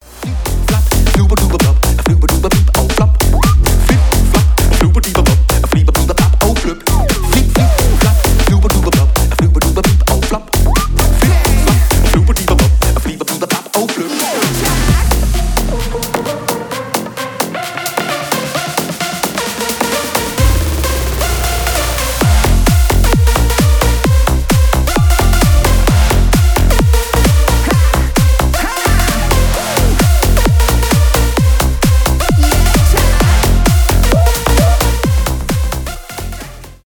ремиксы
танцевальные , зажигательные
веселые